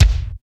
KIK XC.BDR01.wav